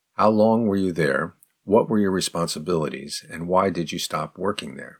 04_advanced_question_slow.mp3